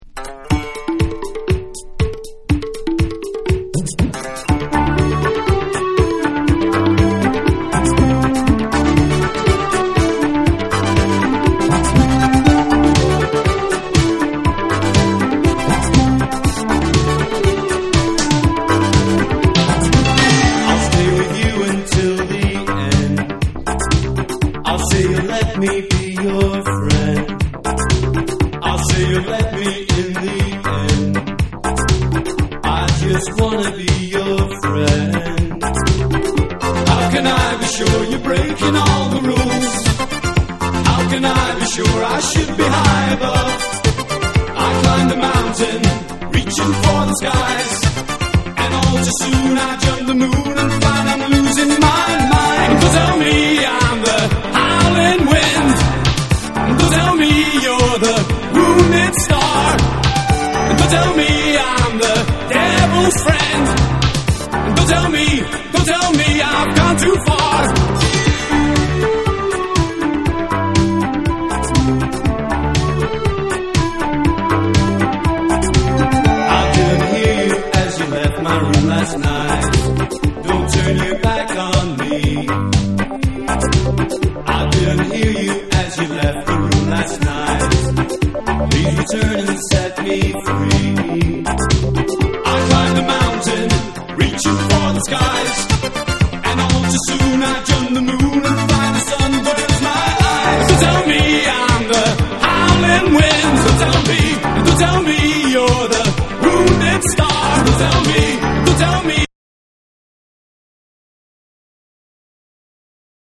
NEW WAVE & ROCK